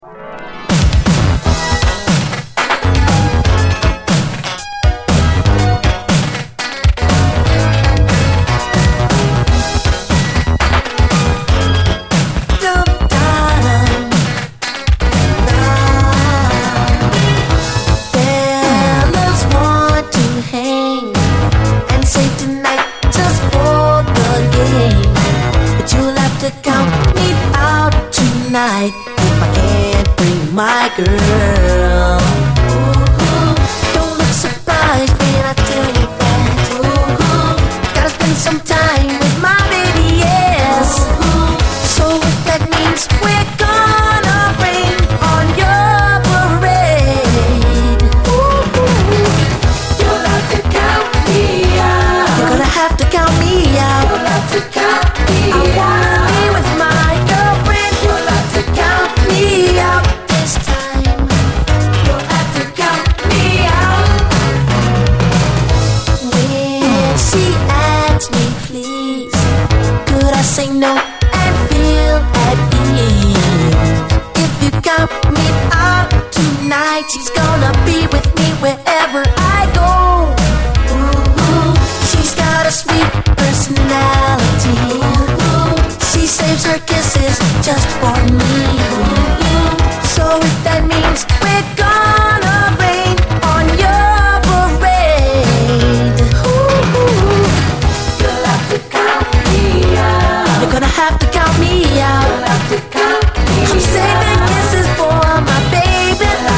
SOUL, 70's～ SOUL, DISCO, 7INCH
キラキラと弾けるシンセ・ソウル・サウンド！